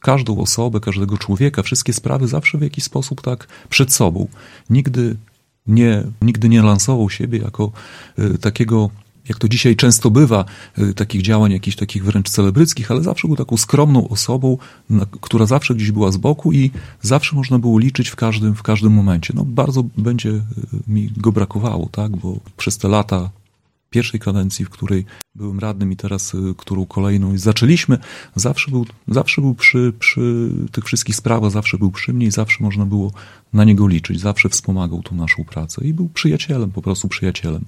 „Życzliwy, skromny, zaangażowany i skrupulatny”, tak Zdzisława Koncewicza, zmarłego suwalskiego samorządowca i związkowca, wspominali dziś w Radiu 5 Zdzisław Przełomiec, przewodniczący Rady Miejskiej w Suwałkach i Jacek Juszkiewicz, wiceprzewodniczący Rady oraz przewodniczący klubu Prawo i Sprawiedliwość.